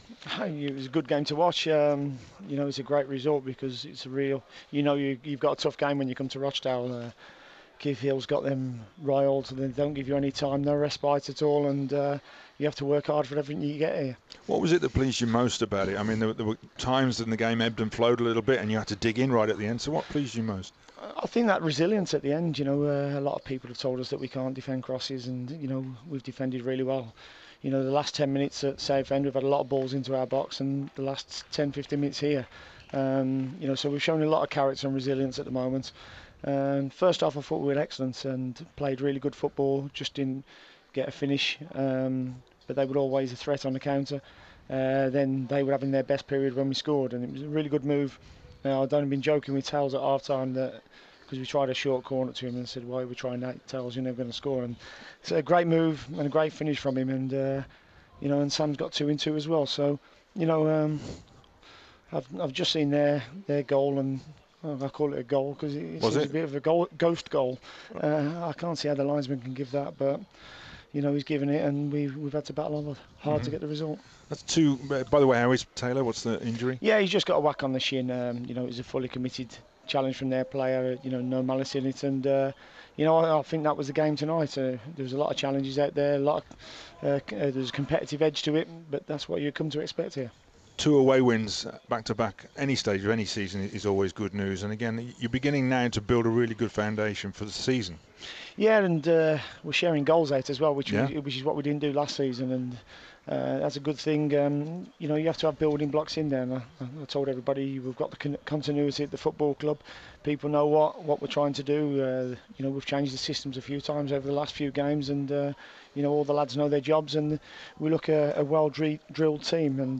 talks to Dean Smith after Rochdale 1 - 2 Walsall